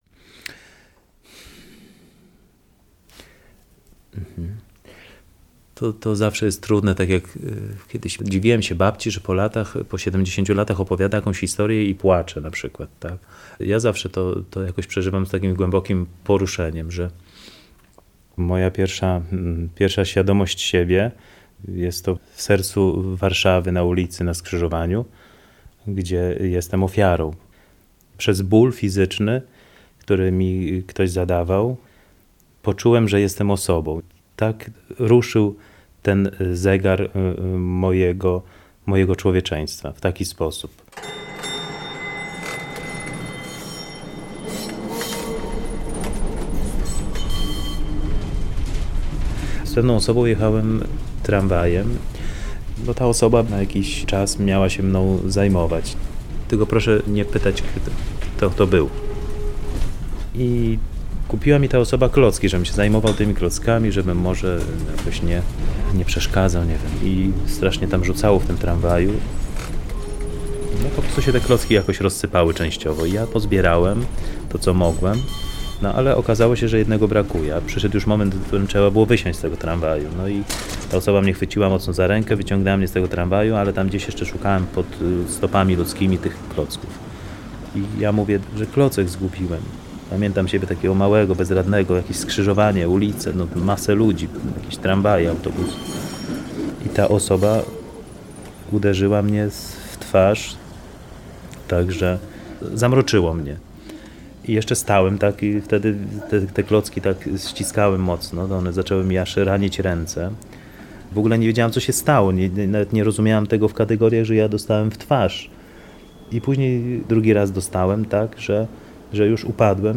Reportaż z wątkiem bożonarodzeniowym.